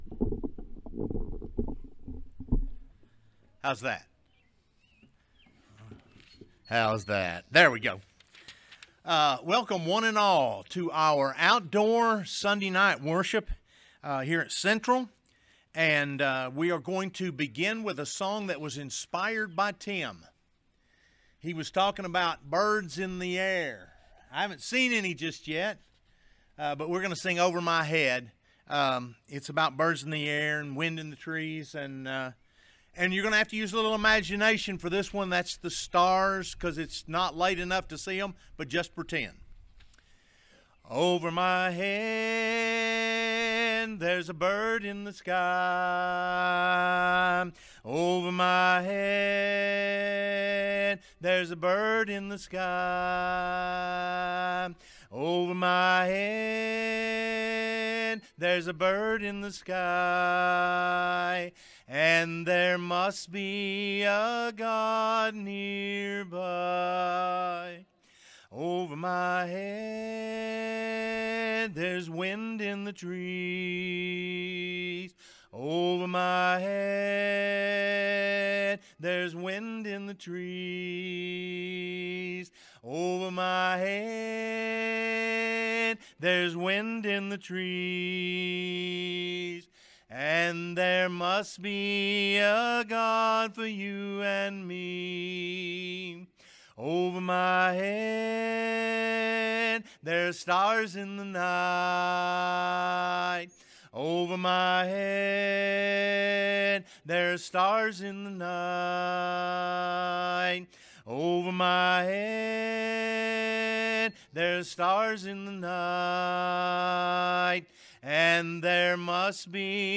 Job 2:9, English Standard Version Series: Sunday PM Service